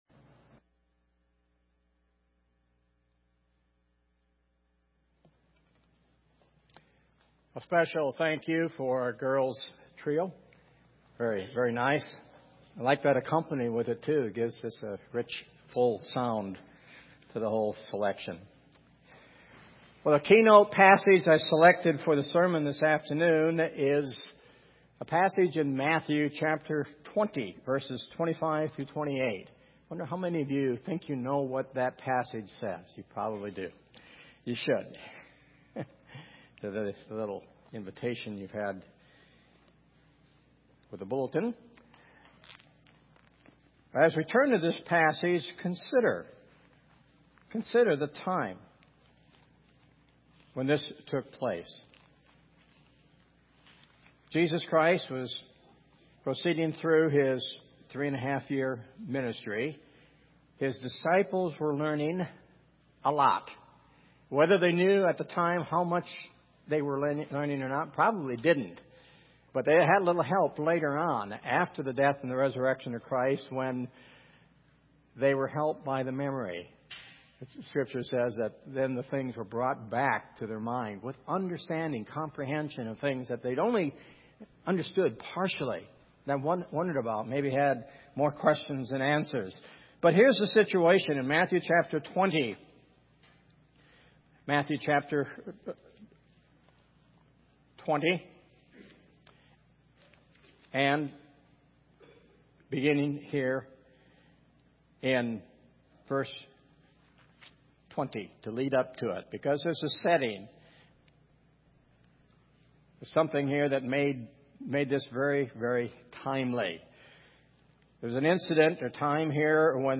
Our keynote passage for the sermon today is located in Matthew, chapter 20, and verses 25 through 28.